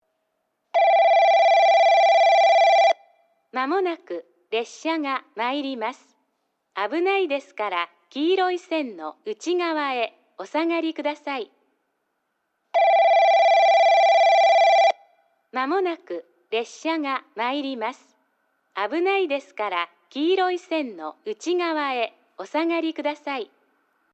・旧放送（メロディ導入前）
1番のりば接近放送　女声 放送は高架化と同時に、九州カンノ型Ｃとなりました。実際の放送は2回流れていました。
スピーカーはＪＶＣラインアレイです。